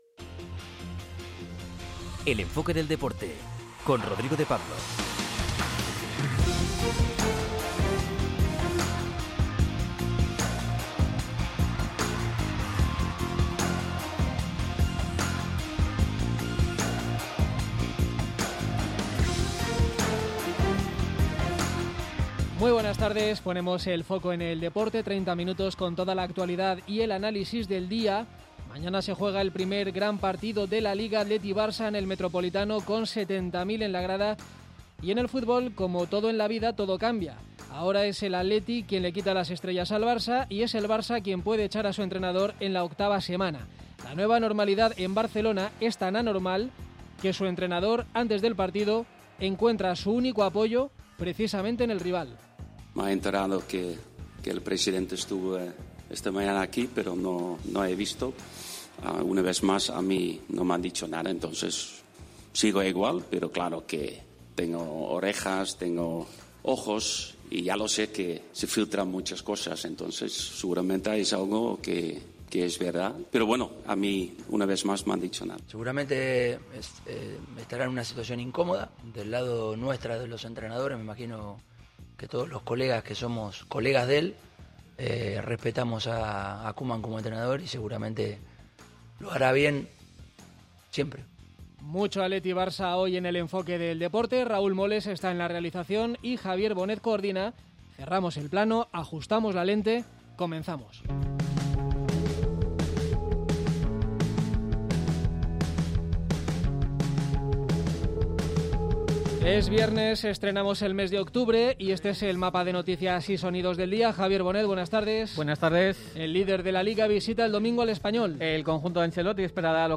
Este sábado se juega el primer gran partido de la temporada, Atlético de Madrid - Barcelona, con el estadio Wanda Metropolitano lleno y con los rojiblancos como claros favoritos. Escuchamos a los dos entrenadores.